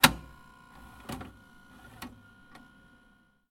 VHS_Stop.wav